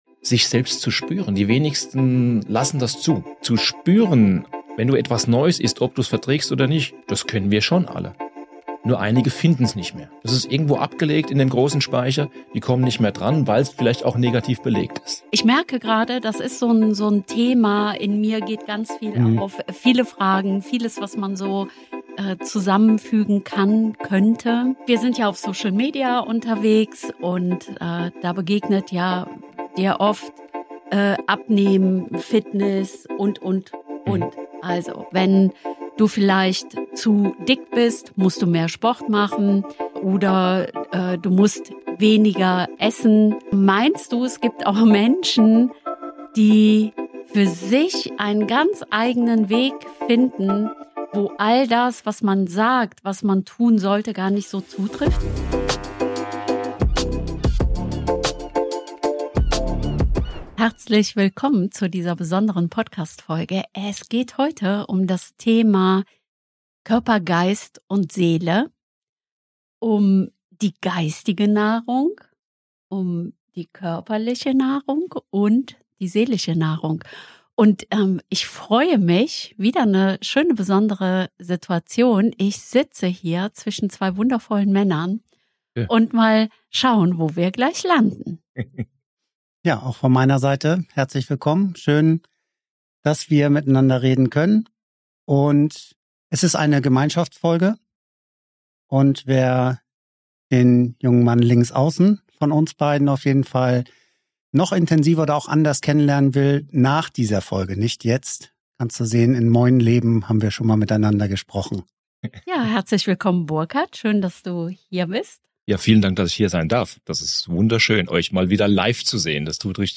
In einem gemeinsamen Gespräch